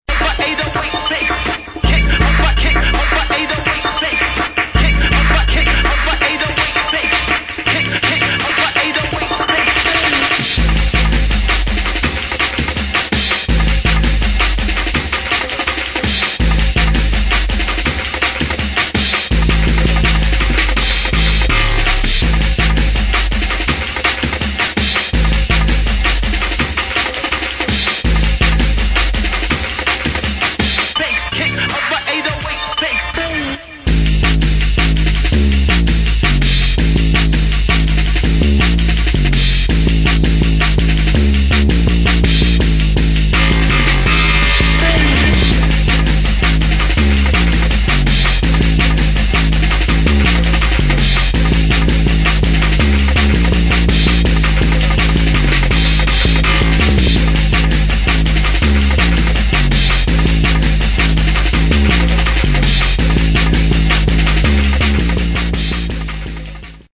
[ DRUM'N'BASS / JUNGLE / OLDSKOOL ]